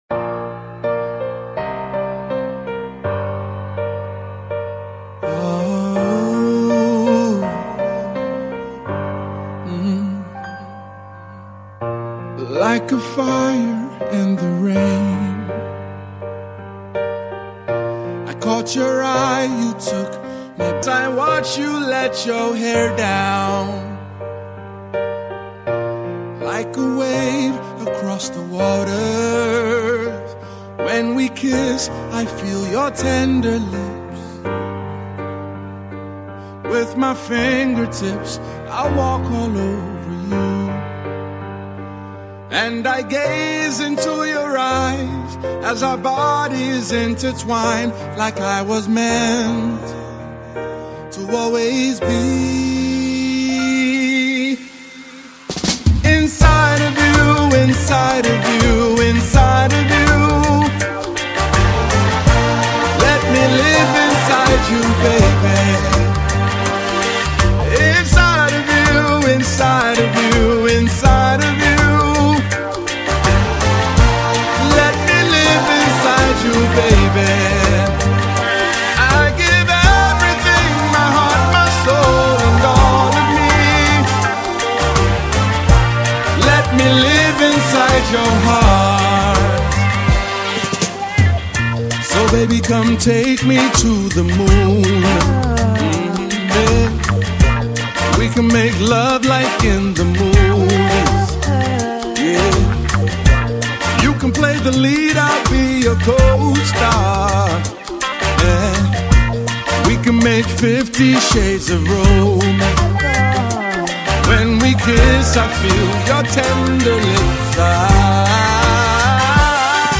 and as you can imagine it is quite the love song.